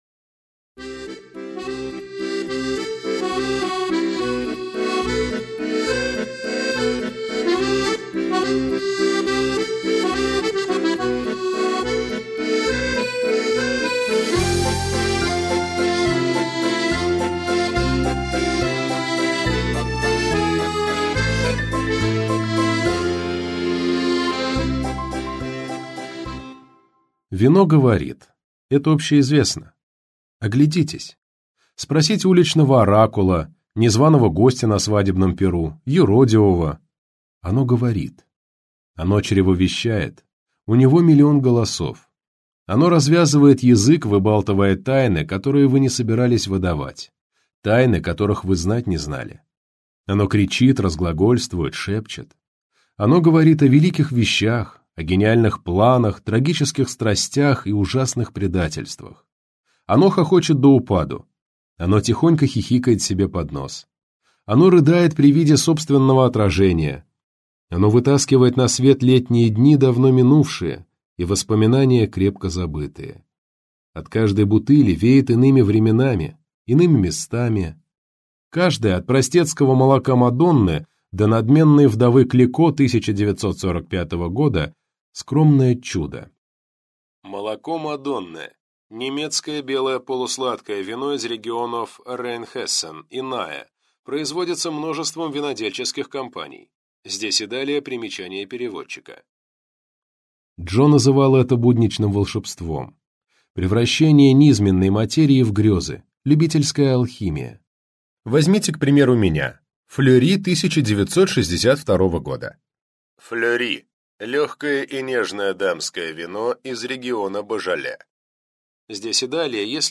Аудиокнига Ежевичное вино - купить, скачать и слушать онлайн | КнигоПоиск